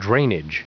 Prononciation du mot drainage en anglais (fichier audio)
Prononciation du mot : drainage